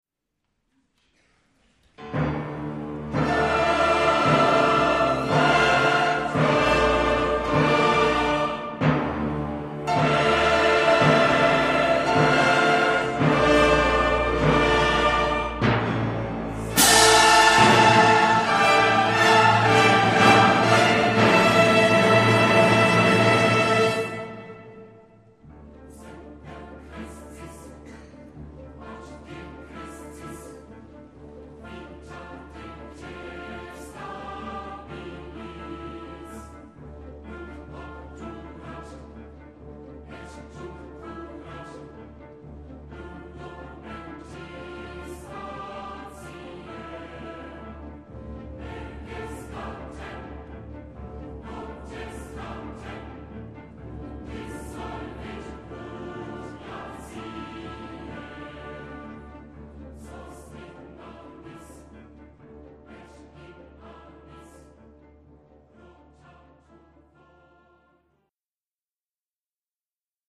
Mp3-Hörproben aus Aufführungen und CDs